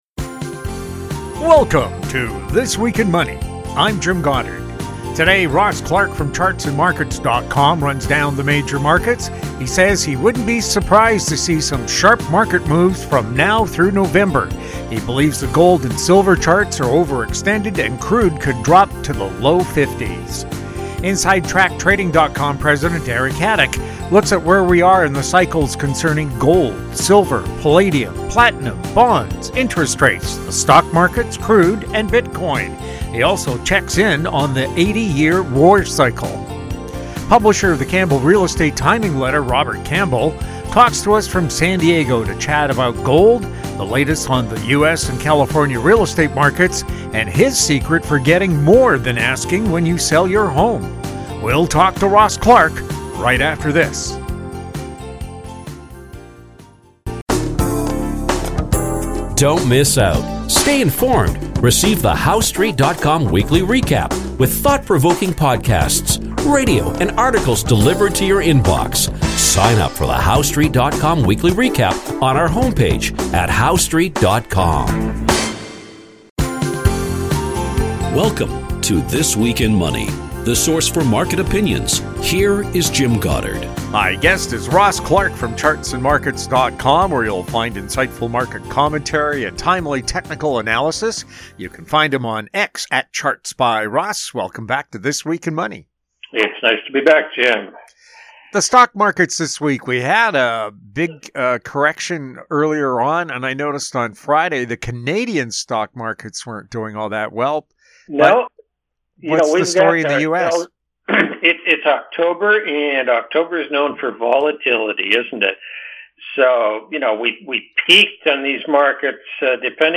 New shows air Saturdays on Internet Radio.